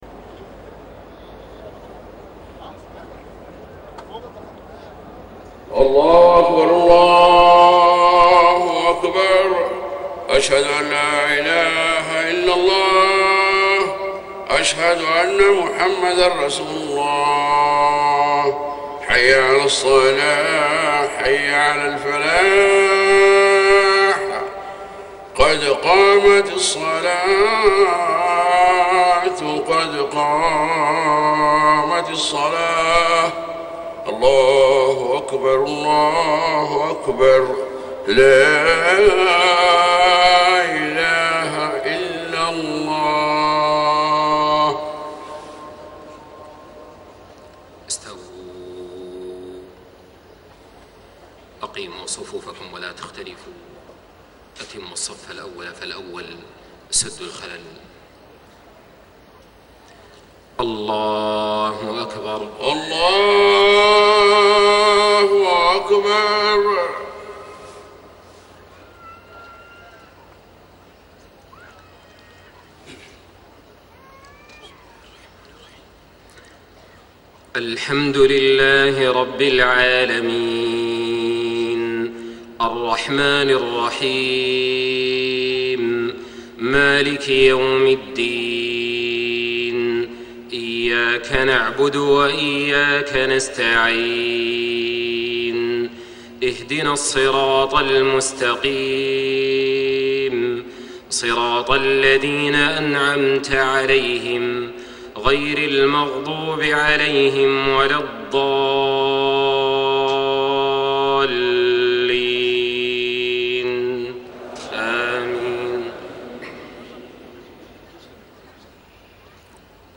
صلاة العشاء 9-7-1434هـ خواتيم سورتي يس و الصافات > 1434 🕋 > الفروض - تلاوات الحرمين